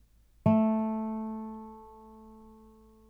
guitare.wav